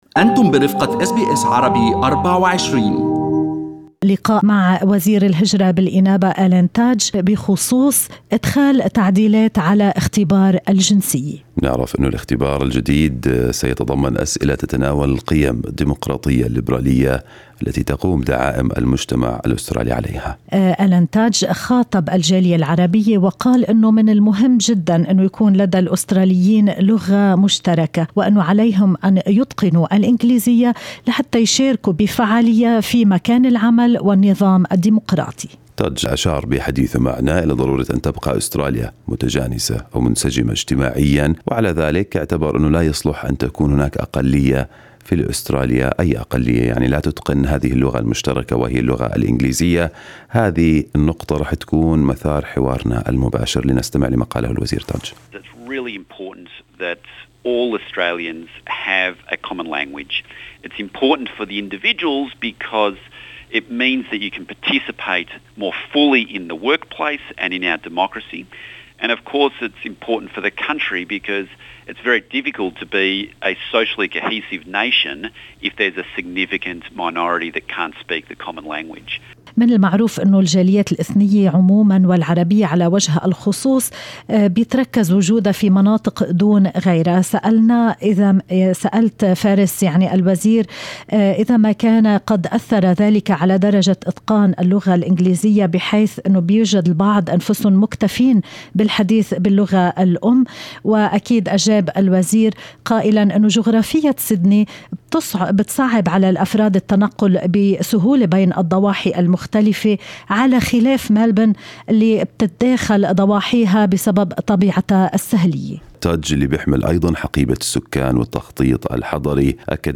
علّق وزير الهجرة بالإنابة آلان تادج - في لقاء خاص مع أس بي أس عربي24- على التغييرات التي ستطال في الفترة المقبلة كلاً من برنامج اللغة الانجليزية الخاص بالمهاجرين والقادمين الجدد، واختبار الجنسية الذي سيتضمن مزيداً من الأسئلة عن القيم الديموقراطية الليبرالية التي يرتكز إليها المجتمع الأسترالي.